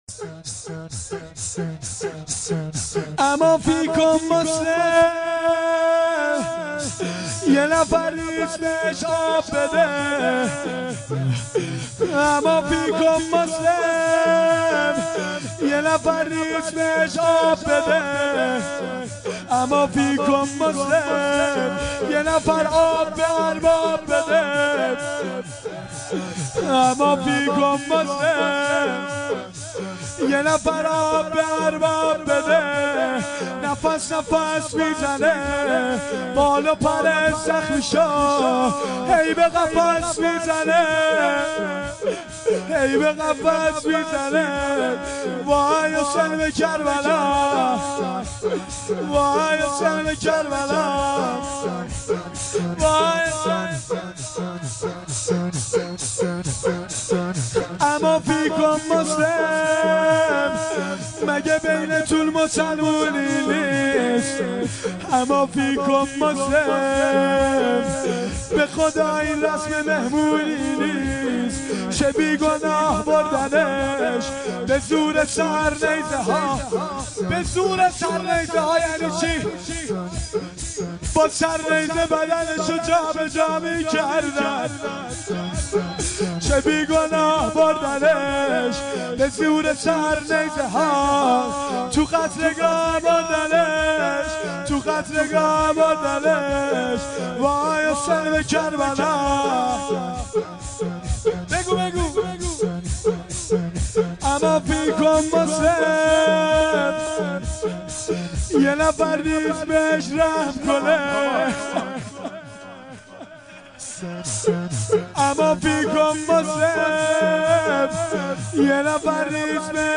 شور لطمه‌زنی
شب پنجم محرم ۱۴۴۱